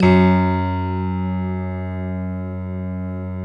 Index of /90_sSampleCDs/Optical Media International - Sonic Images Library/SI1_Six Pianos/SI1_Distantpiano